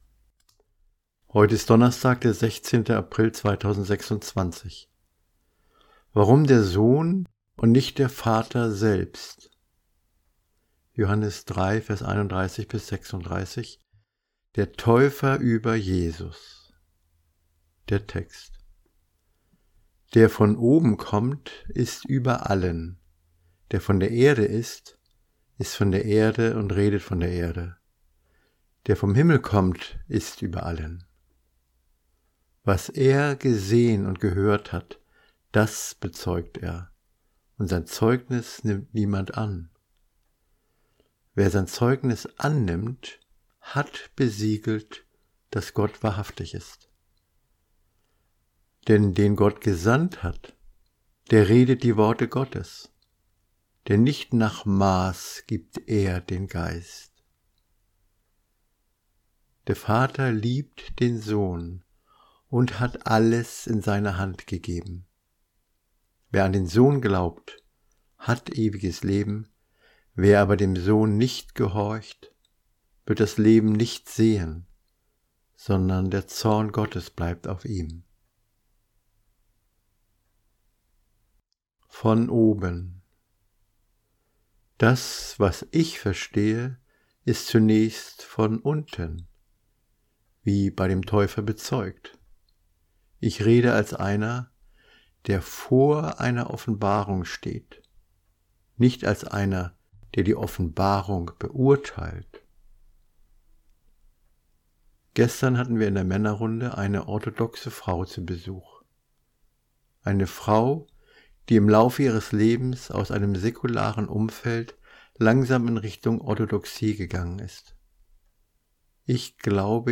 Ich erinnere daran, dass dies eine Andacht ist – keine theologische Ausarbeitung.